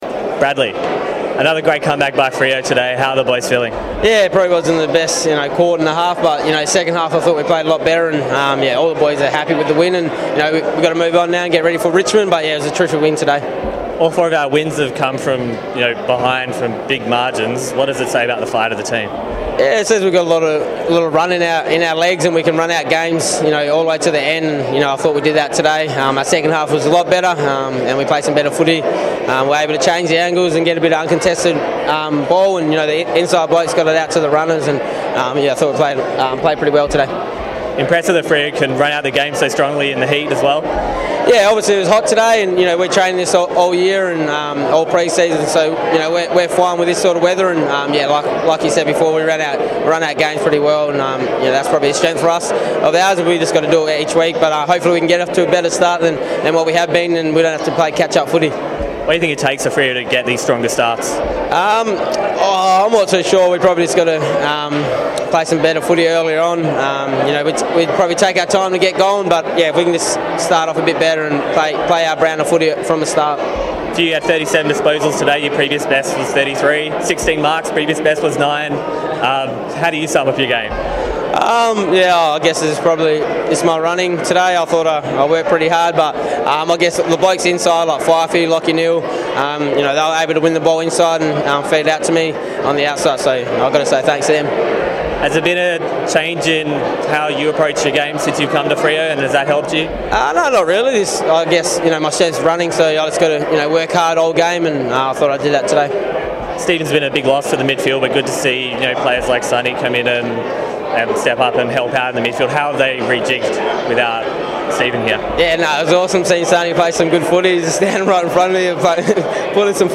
Bradley Hill speaks to Docker TV after Freo's win over the Bombers.